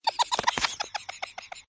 avatar_emotion_laugh.ogg